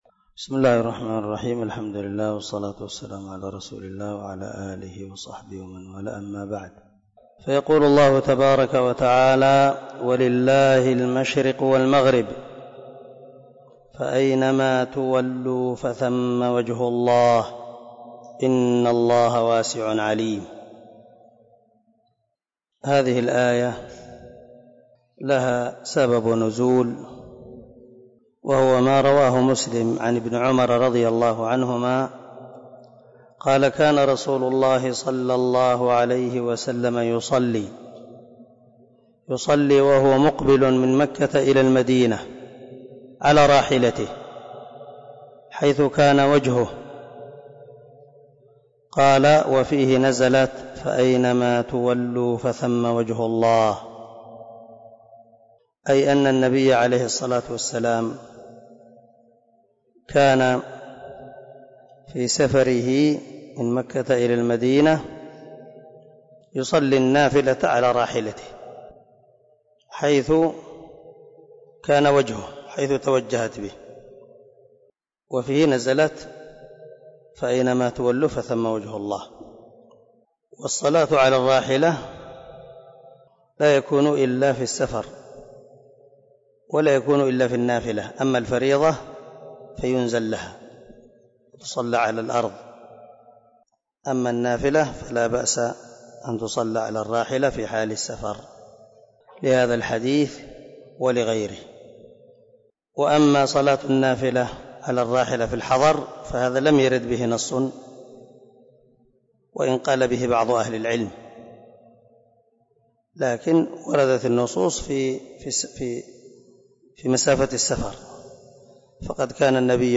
048الدرس 38 تفسير آية ( 115 ) من سورة البقرة من تفسير القران الكريم مع قراءة لتفسير السعدي